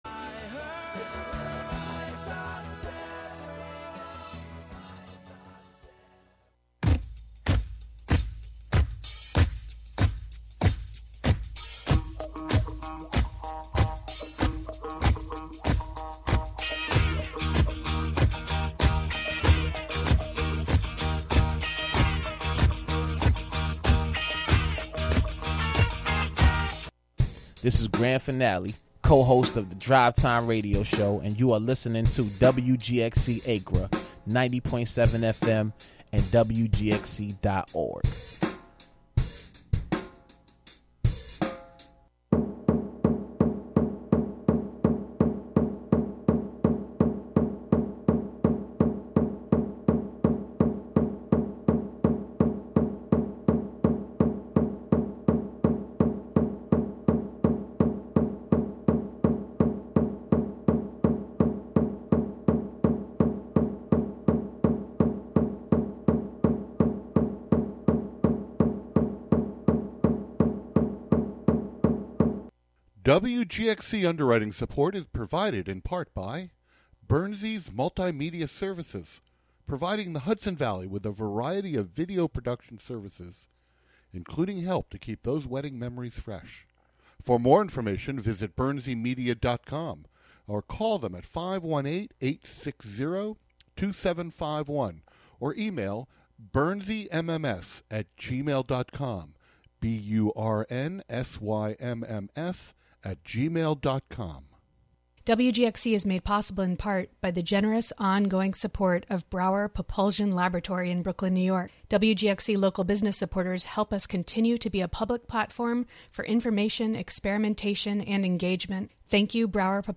“Nuestra Música,” “Our Music,” presents the rhythms and folklore of Latin America, Spain, Portugal and Cape Verde. From Tango to Flamenco and all Latinx music in between. The show explores the native instruments, languages and traditions played in 22 countries and one territory.